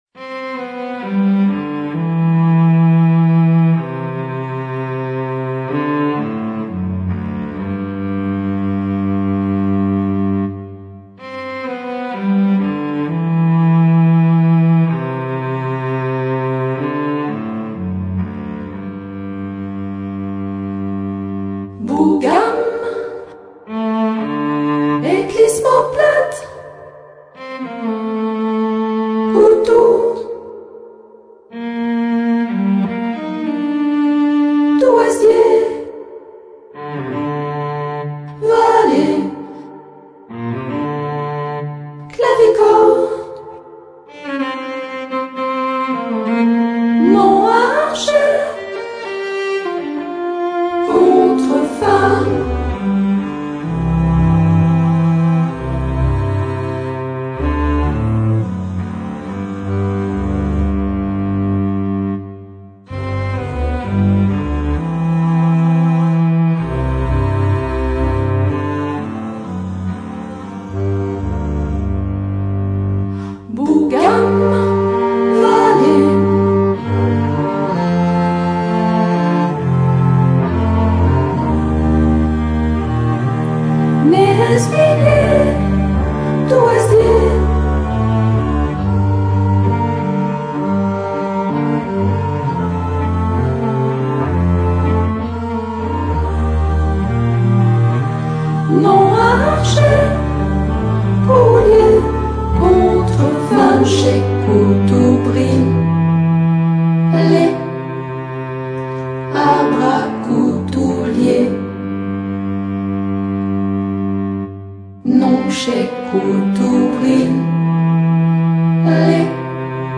Sculpture sonore